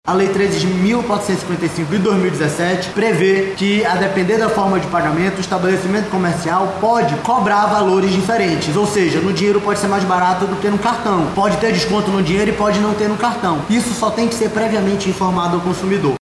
O diretor-presidente do Procon Amazonas, Jalil Fraxe, explica que os descontos podem variar de acordo com a forma de pagamento.